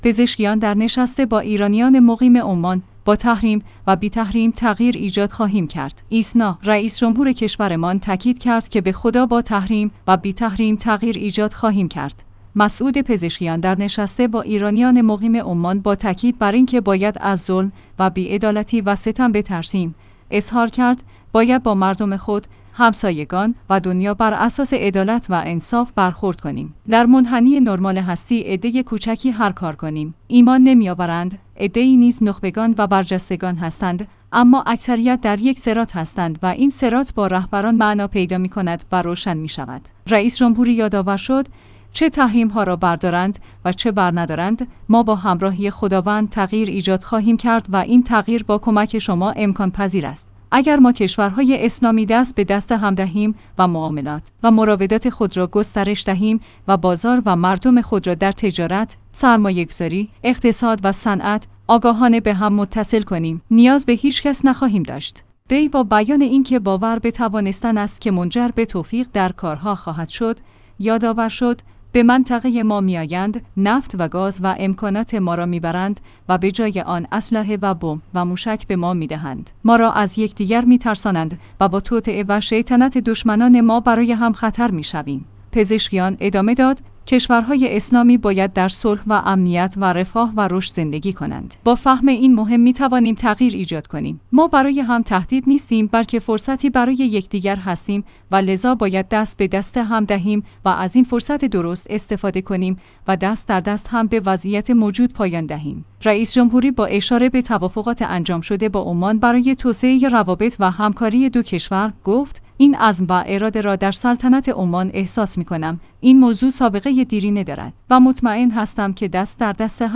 پزشکیان در نشست با ایرانیان مقیم عمان: با تحریم‌ و بی‌تحریم تغییر ایجاد خواهیم کرد